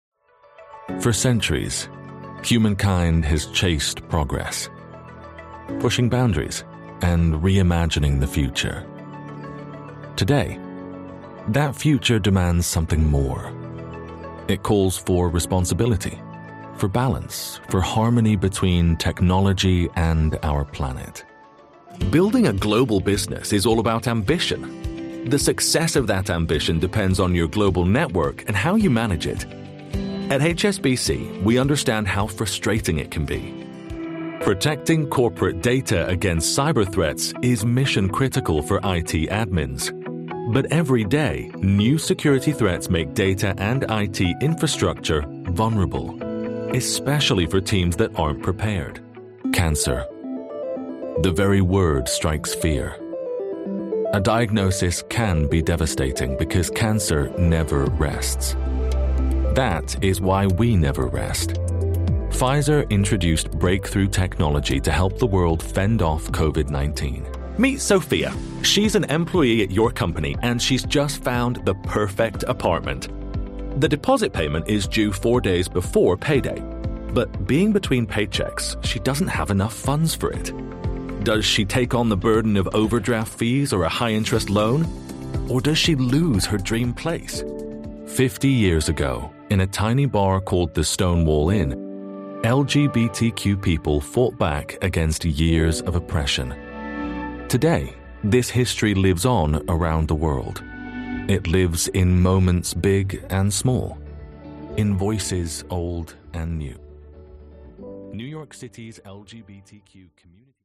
Inglés (internacional)
Vídeos corporativos
Micrófono TLM 103
Cabina de aislamiento de sonido especialmente diseñada